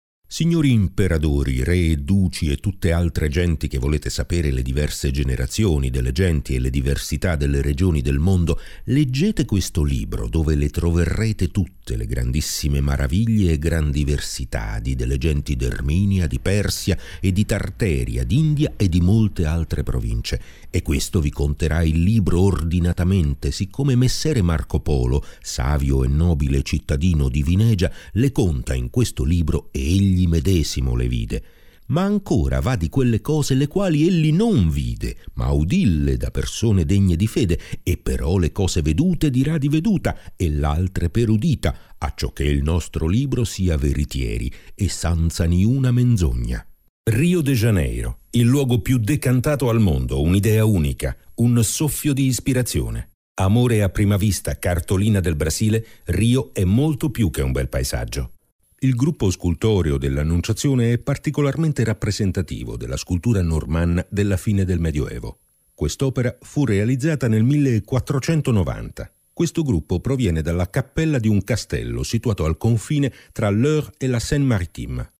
I'm a native Italian voice talent, with a solid background in radio-tv journalism and documentary making, and a real italian regional accent-free voice: mid/low range, 30s to 50s, informative, elegant, believable, professional, yet warm, friendly, seductive when needed.
I provide studio-quality recordings with .wav or .mp3 fast, reliable, safe online delivery.
Sprecher italienisch. Middle age deep voice
Sprechprobe: Werbung (Muttersprache):